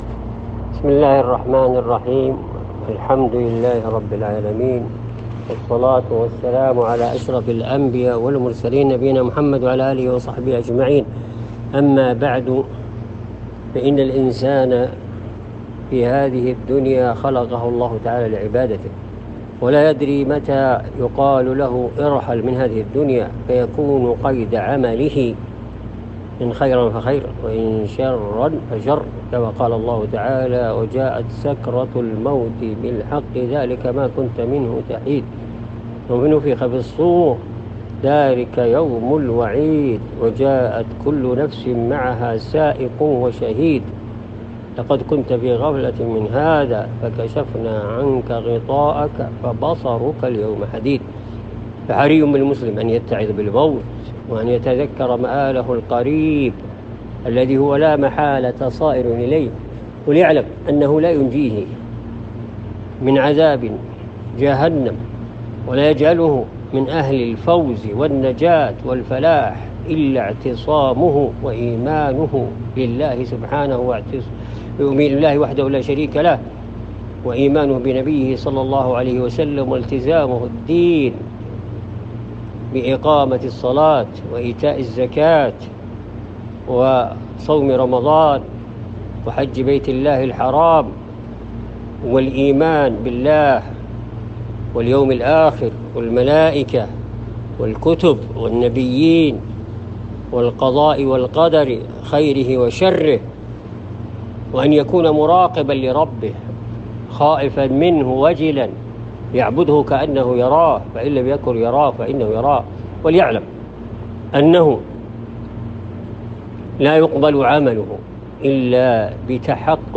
كلمة وموعظة في أهمية الالتزام بالسنة والتحذير من البدع وأهلها